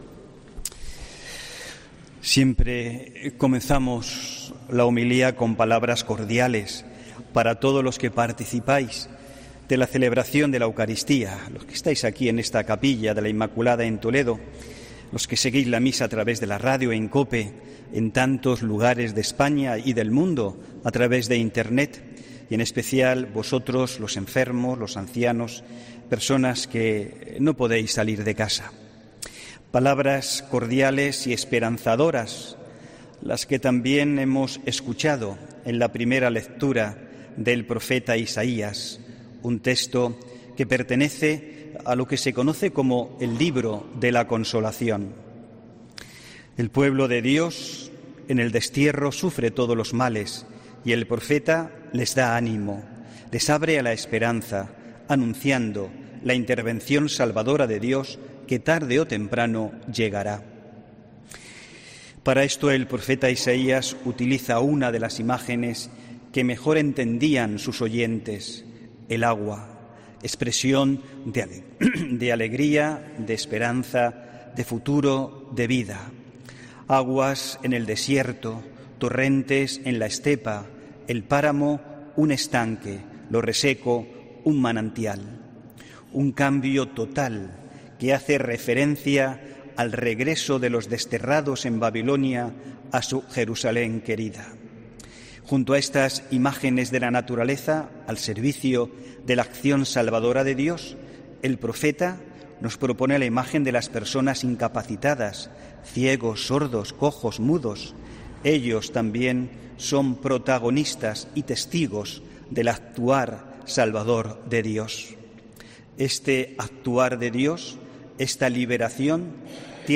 HOMILÍA 5 SEPTIEMBRE 2021